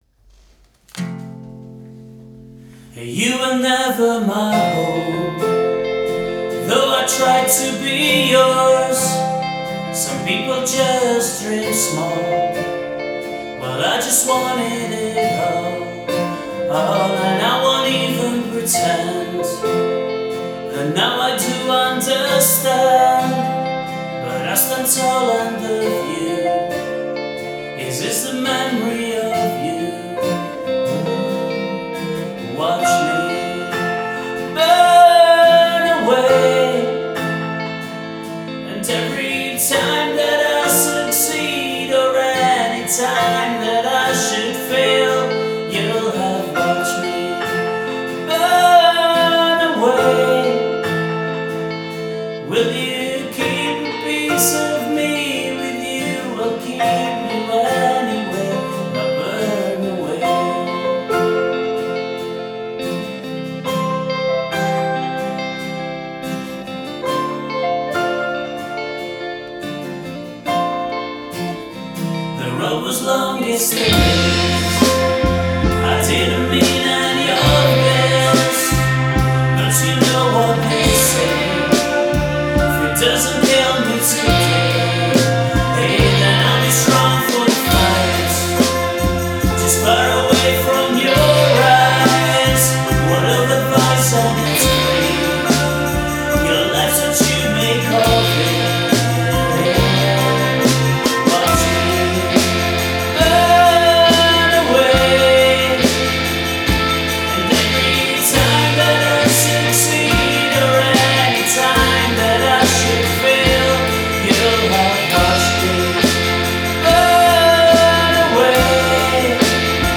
vocals, guitars, bass, drums, keyboards, percussion